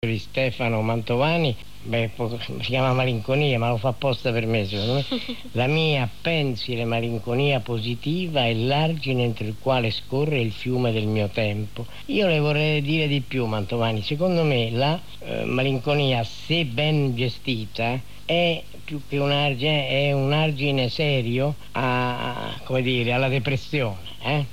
L’ audio è tratto dal programma radiofonico serale “L’uomo della notte”, su Radio 1 Rai condotto da Maurizio Costanzo.